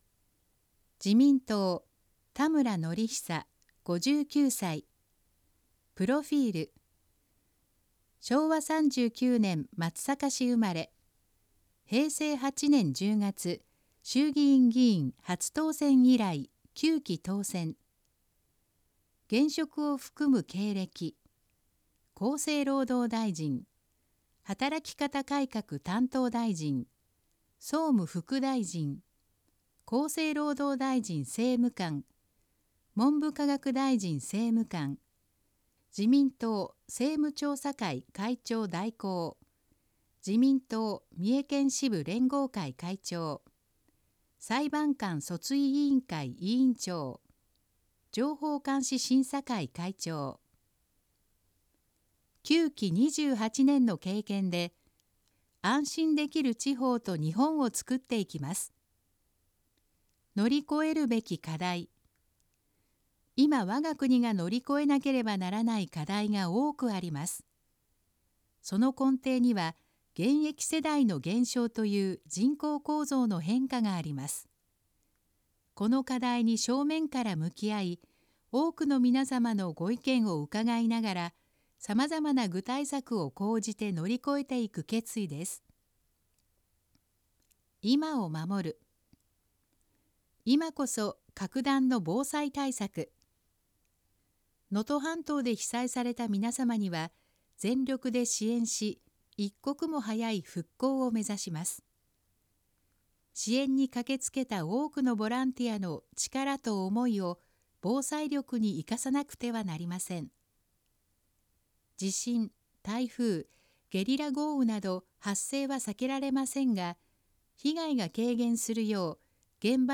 選挙公報の音声読み上げ対応データ（候補者提出）